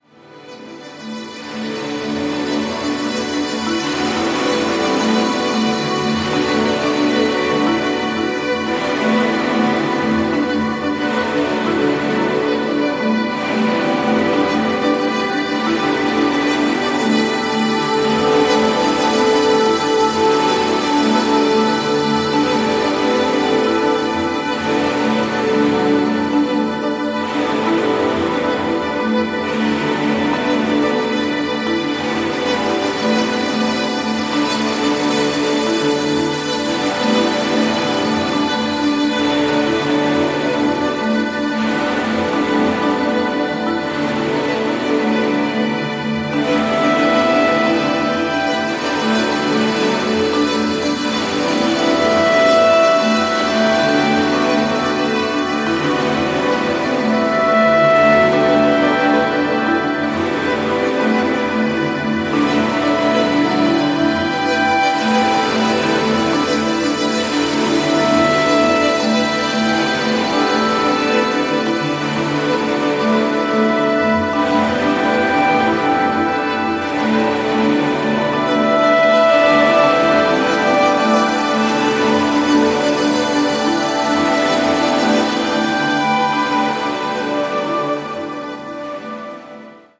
豊かに反復するドローン、細やかなピアノの残響、丹念に作り上げられた音像と１曲１曲がドラマチックに満ちてゆく。